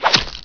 1 channel
meleehit1.wav